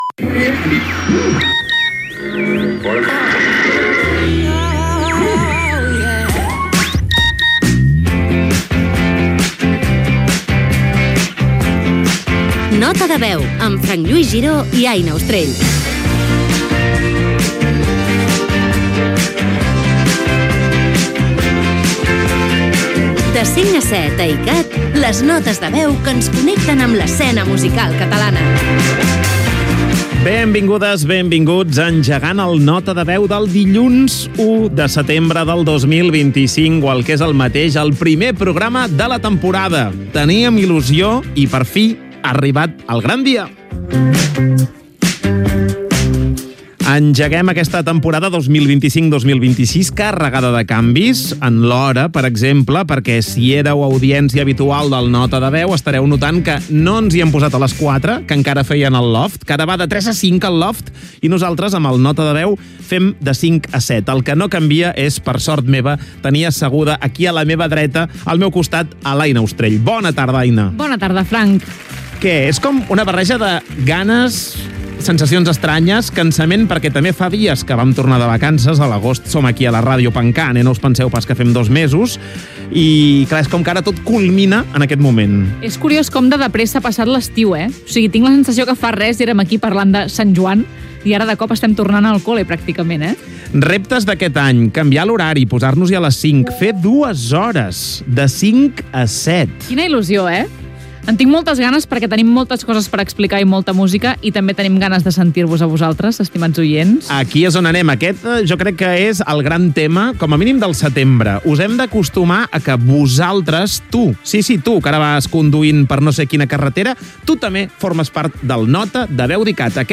Inici del primer programa de la temporada 2025-2026.
Entreteniment